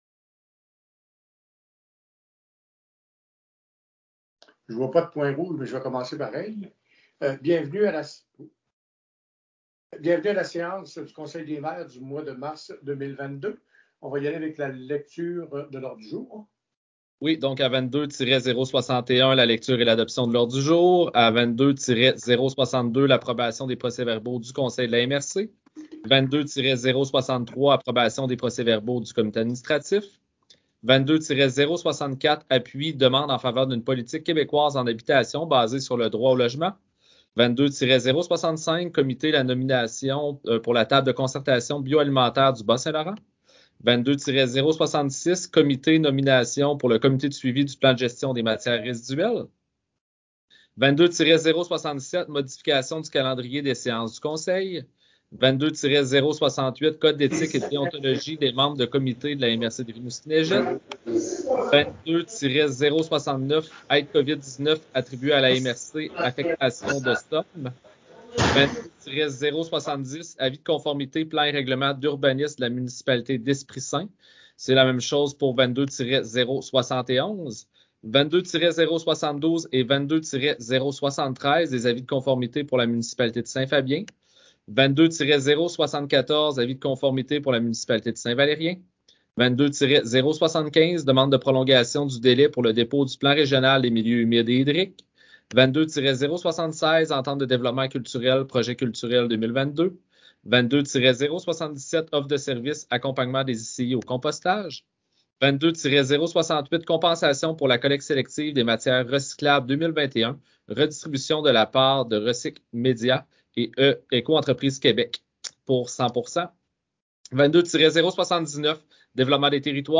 Voici l’enregistrement du conseil de la MRC de Rimouski-Neigette, tenu le 9 mars 2022.